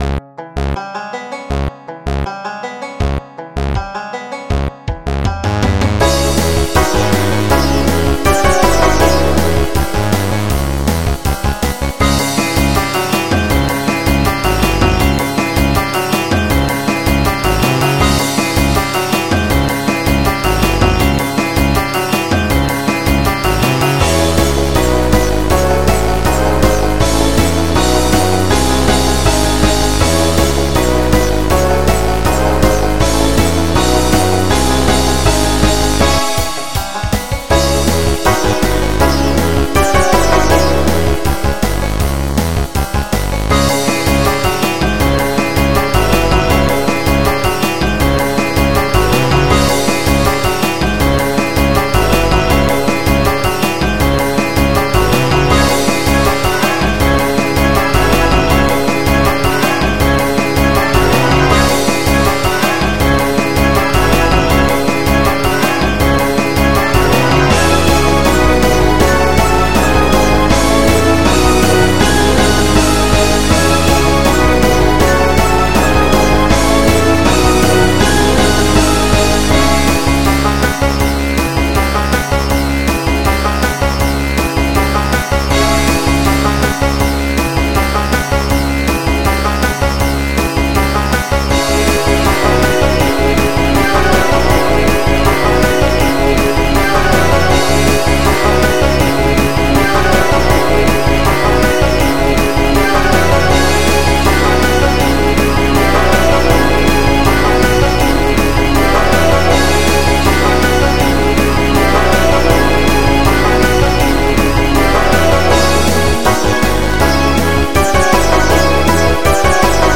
MIDI 45.36 KB MP3 (Converted)